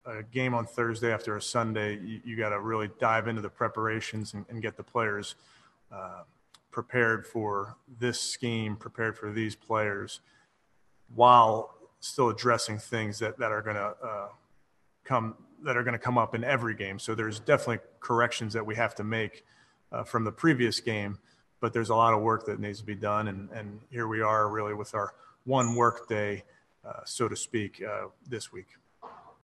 Browns head coach Kevin Stefanski spoke on playing on a short week: